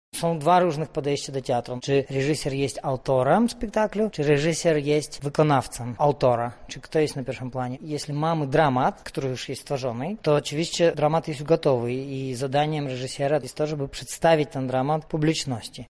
Dużo zależy od tego jaki jest cel spektaklu– tłumaczy Ivan Wyrypajew, reżyser.